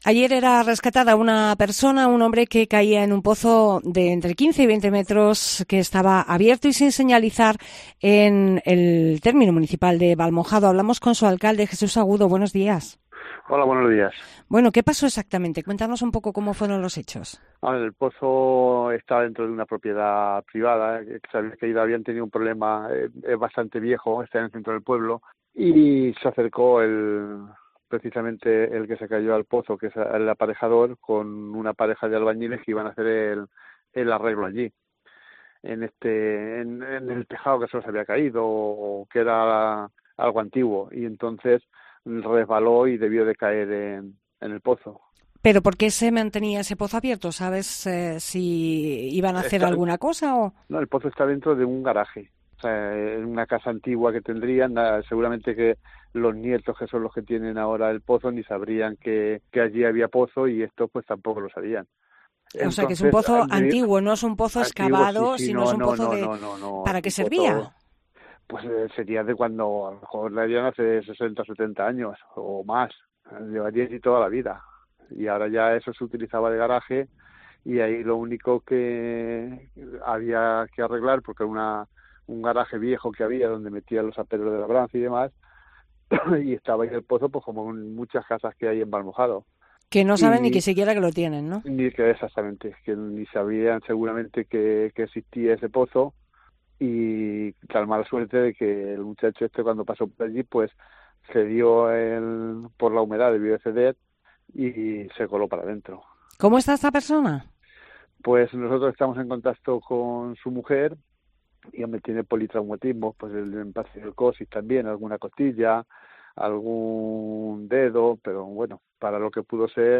Declaraciones de Jesús Agudo, alcalde de Valmojado